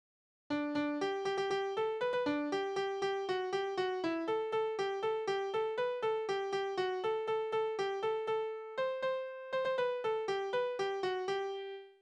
Necklieder: Reise der Schneider nach Niederland
Tonart: G-Dur
Taktart: 3/8
Tonumfang: Septime
Besetzung: vokal